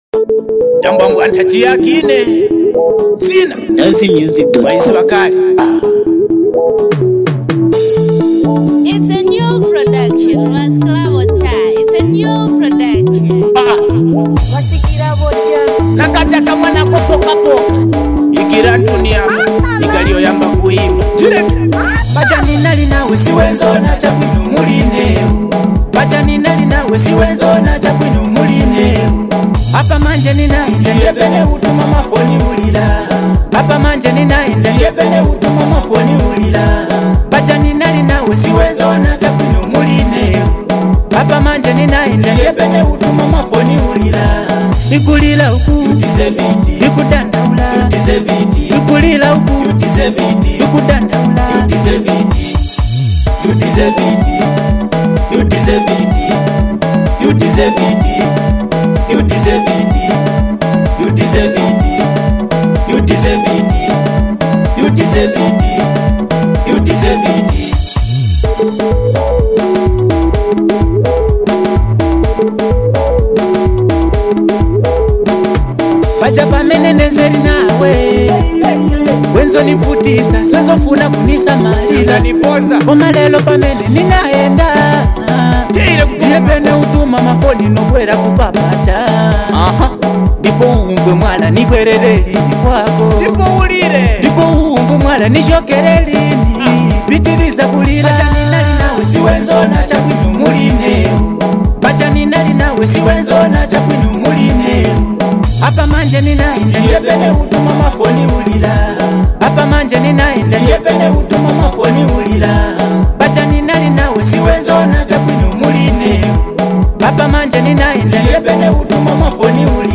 DanceHall Song